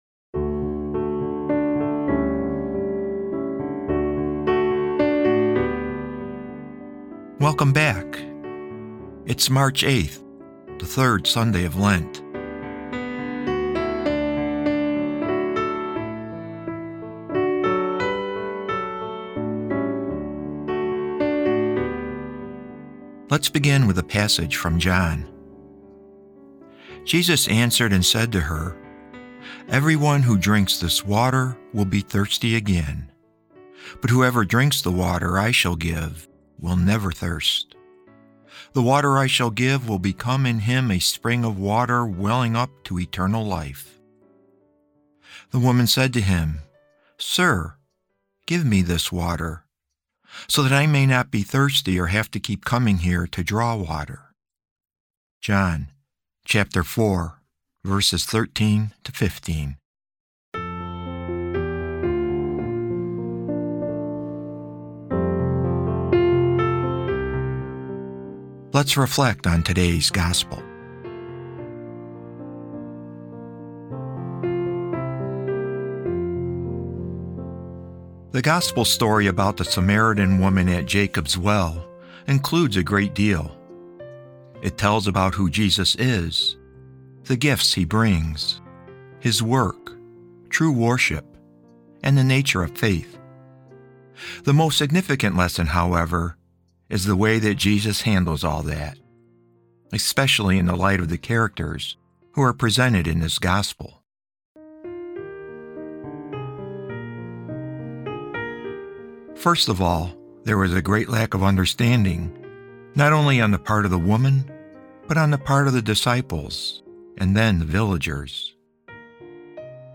Today's episode of Sundays with Bishop Ken is a reading from The Little Black Book: Lent 2026.